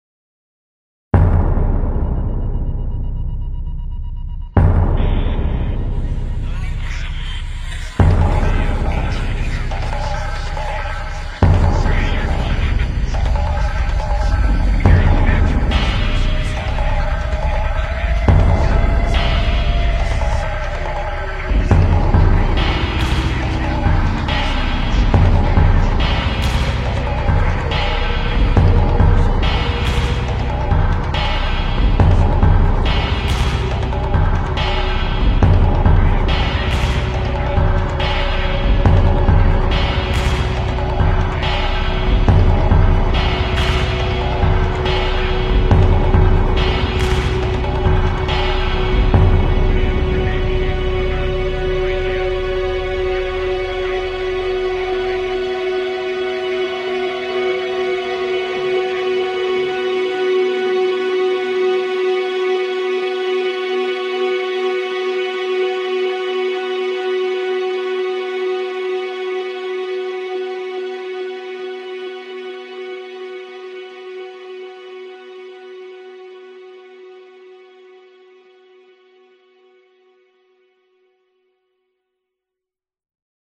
Pop Rock, Alternative Rock